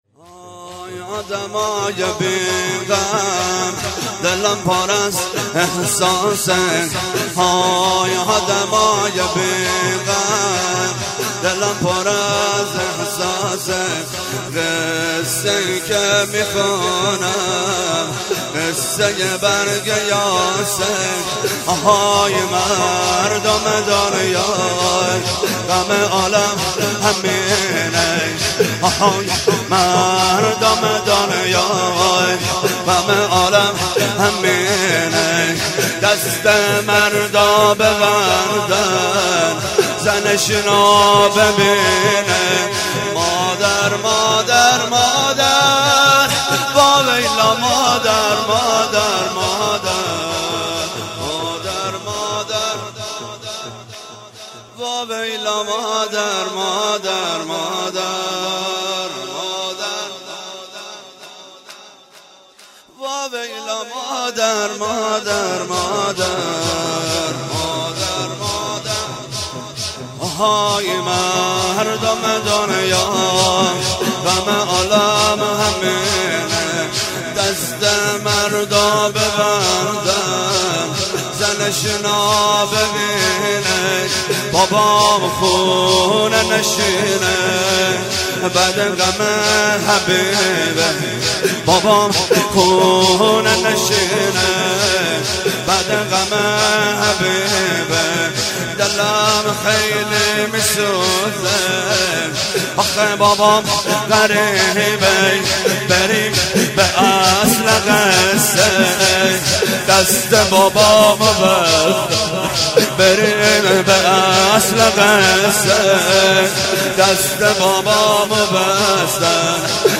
شور
شب اول ویژه برنامه فاطمیه دوم ۱۴۳۹